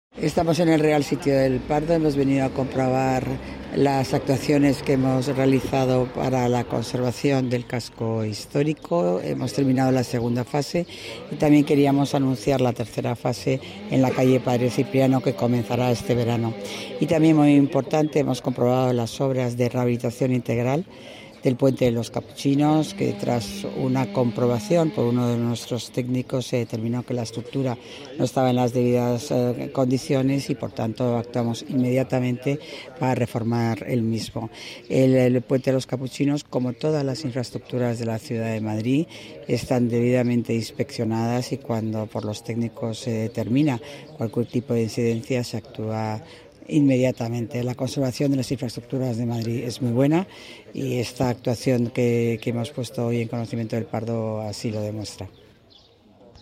AUDIO-Garcia-Romero-visita-el-casco-historico-de-El-Pardo.mp3